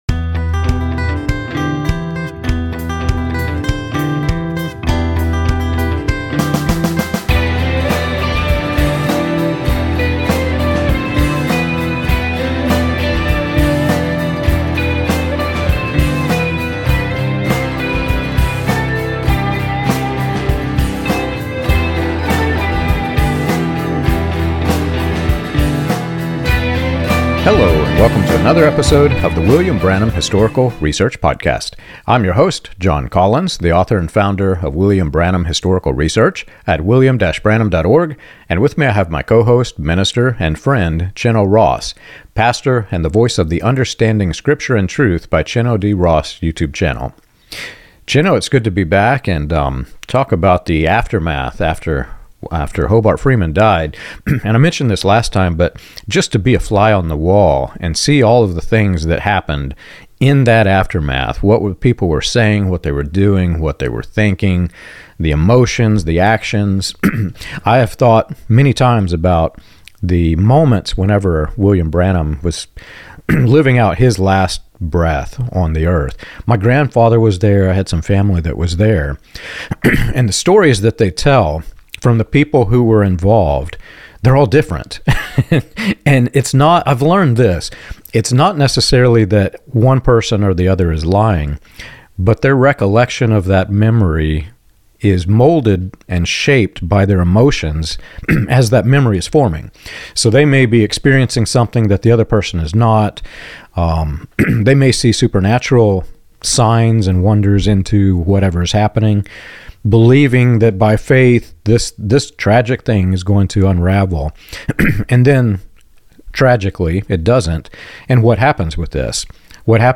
The conversation contrasts biblical models of leadership, death, mourning, and succession with the confusion, secrecy, and denial that followed Freeman's death.